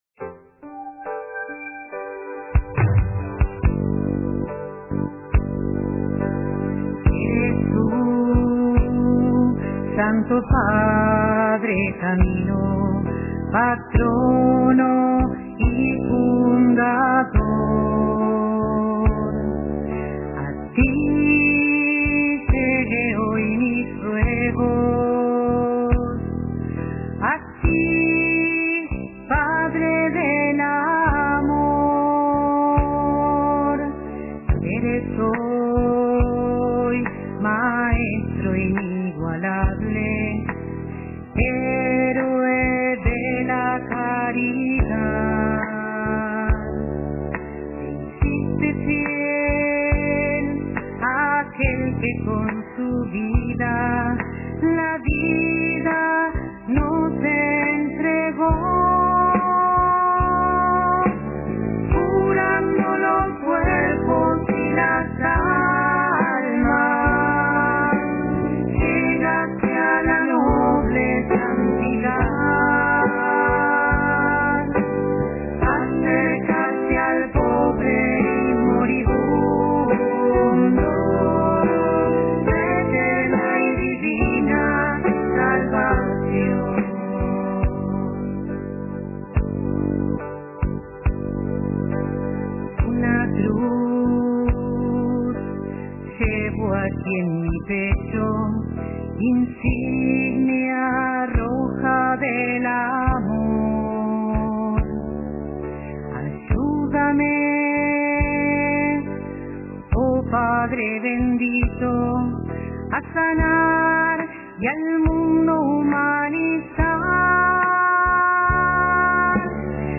Padre del Amor (Canción